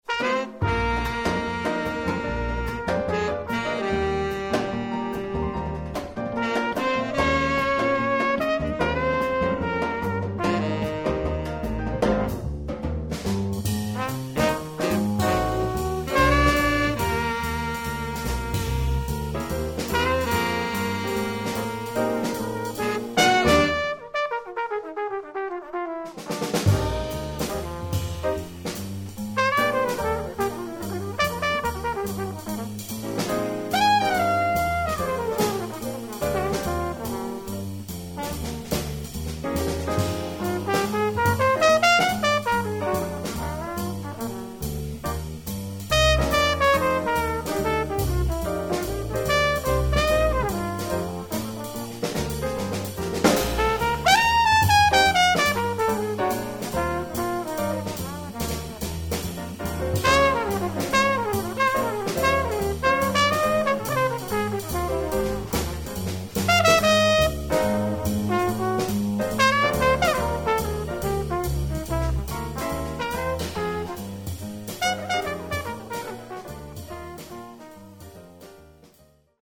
trumpet, flugelhorn
tenor sax.
piano
bass
drums
alto sax.